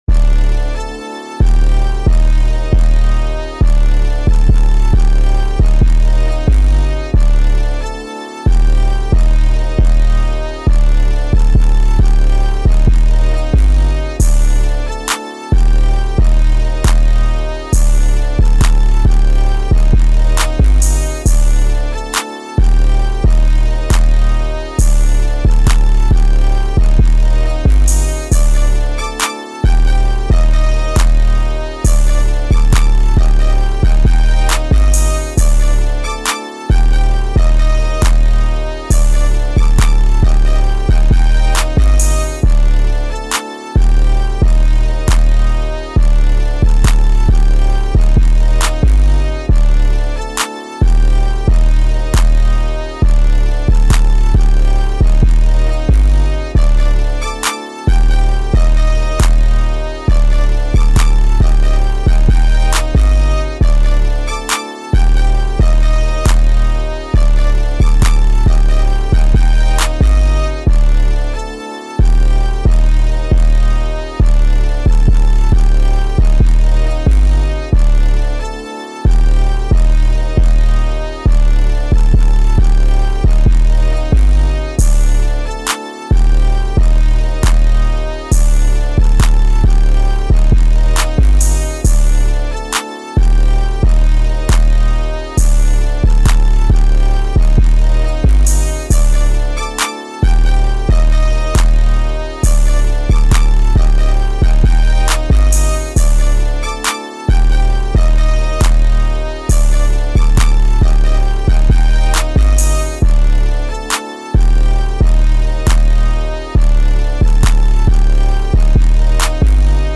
2024 in Official Instrumentals , Rap Instrumentals